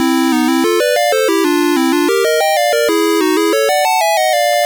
One of the jingles that plays at the start of a level
Source Recorded from the Sharp X1 version.